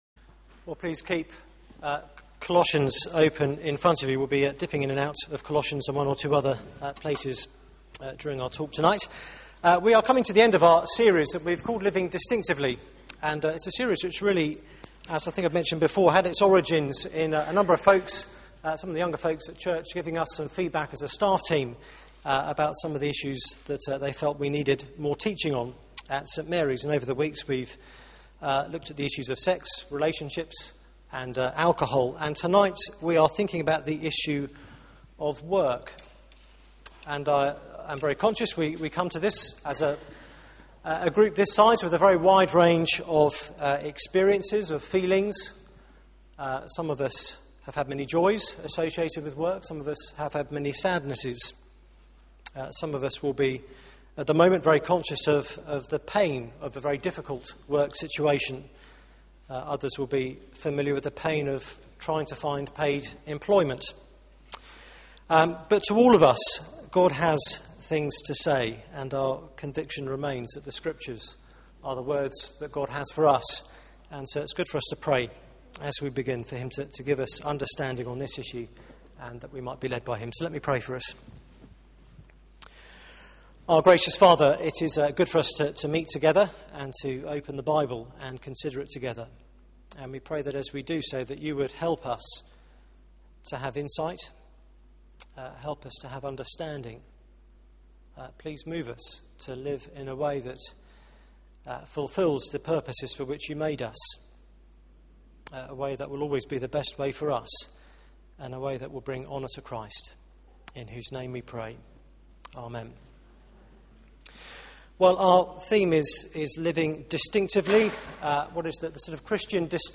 Media for 6:30pm Service on Sun 25th Sep 2011 18:30 Speaker
Series: Living distinctively Theme: A distinctive view of work Sermon